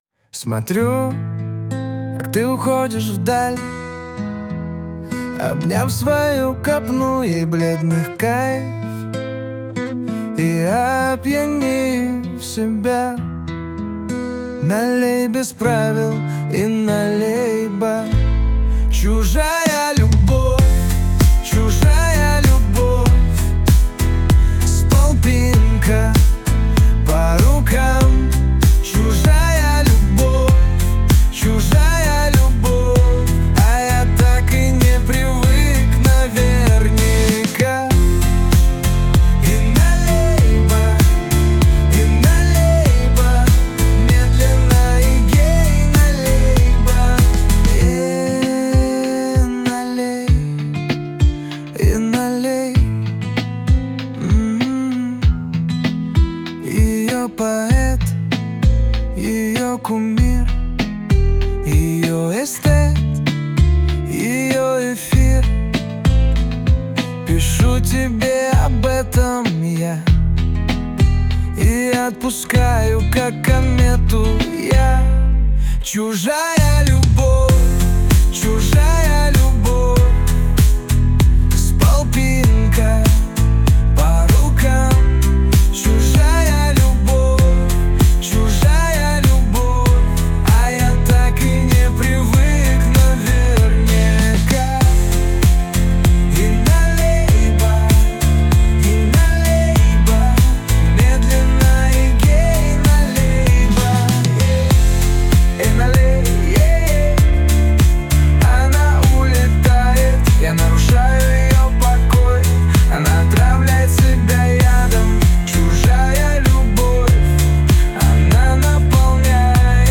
RUS, Romantic, Lyric, Rap | 17.03.2025 16:32